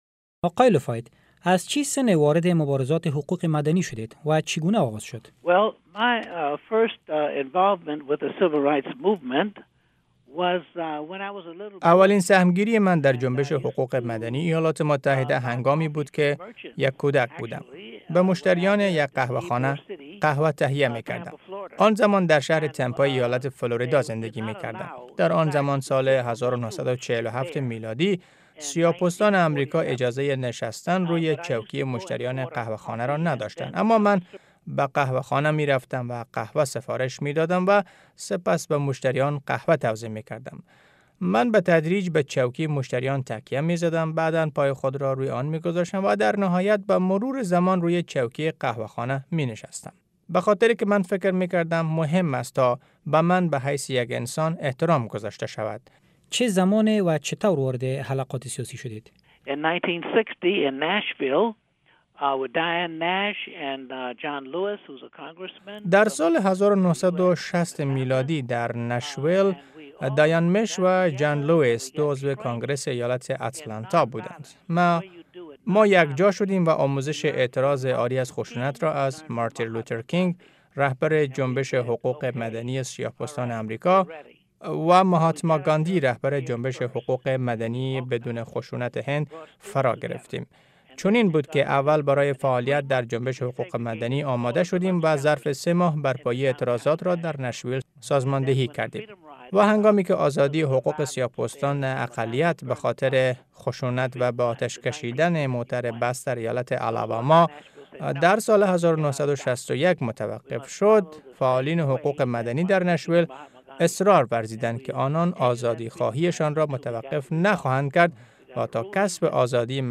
مصاحبه ها
Bernard LaFayette Interview